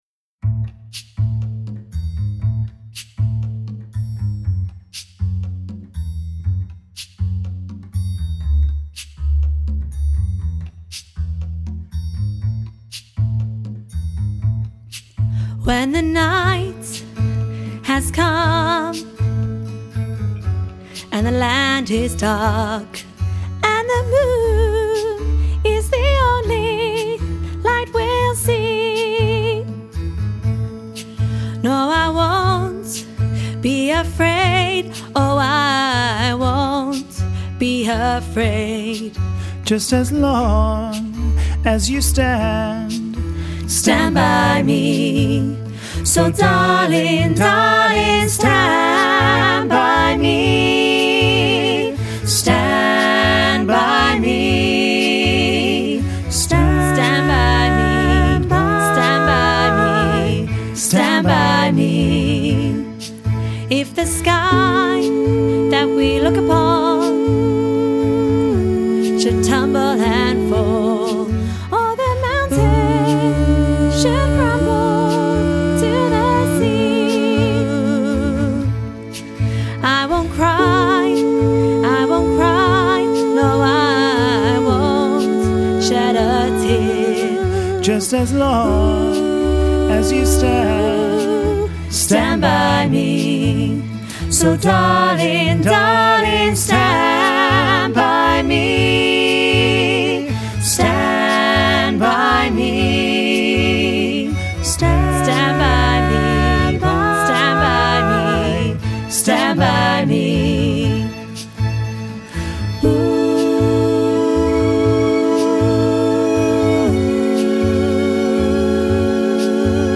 stand-by-me-full-mix.mp3